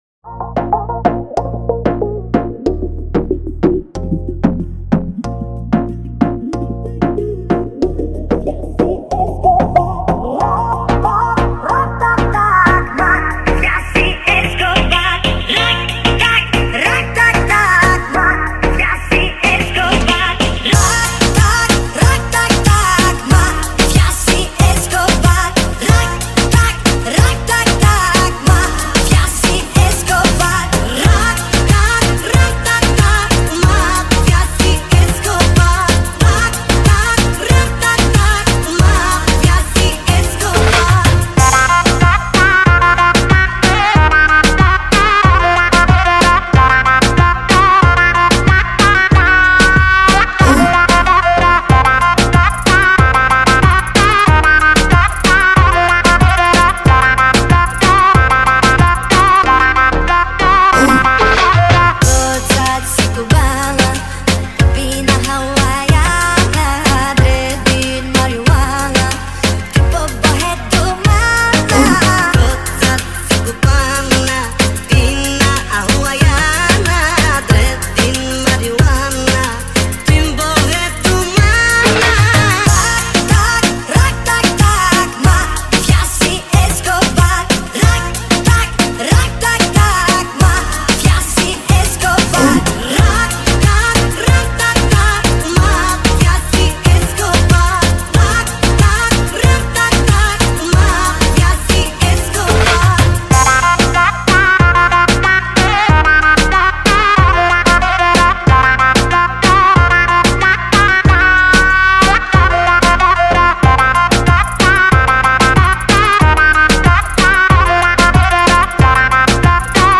ژانر: پاپ/رپ
🎵 نام آهنگ : ریمیکس شاد برای رقص و عروسی